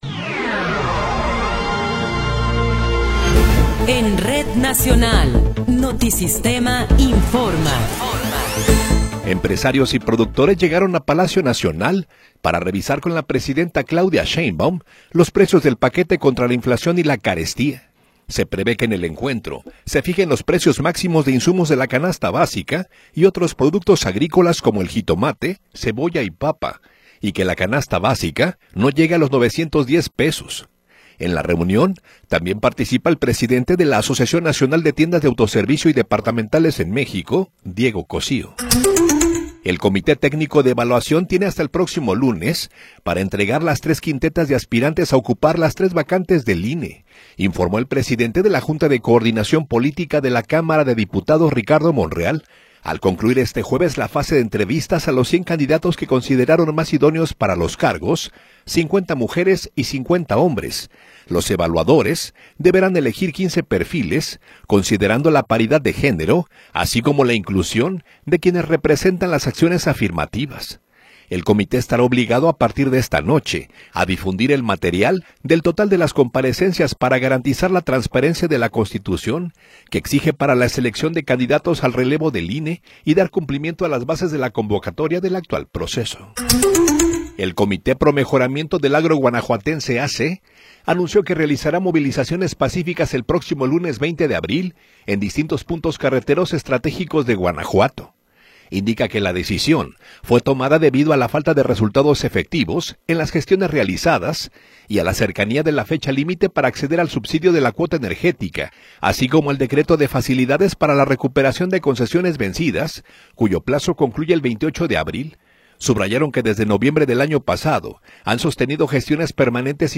Noticiero 19 hrs. – 16 de Abril de 2026
Resumen informativo Notisistema, la mejor y más completa información cada hora en la hora.